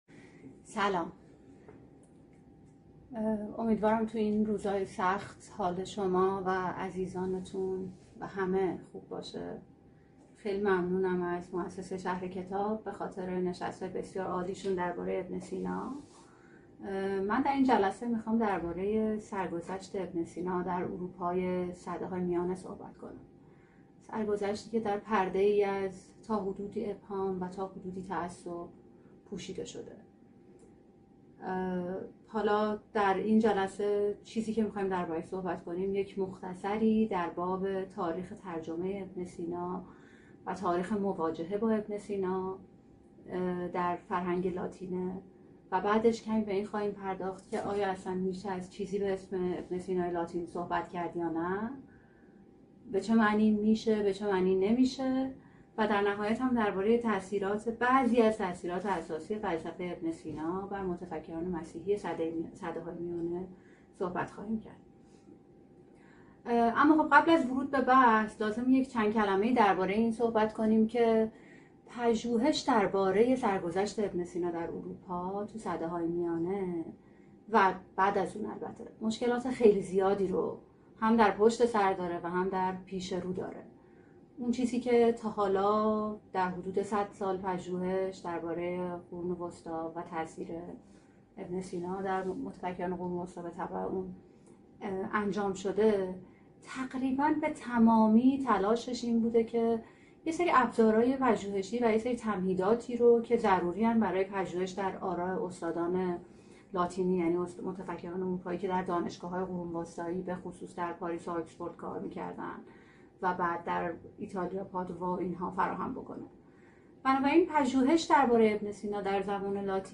این درس‌گفتار به صورت مجازی از اینستاگرام شهر کتاب پخش شد .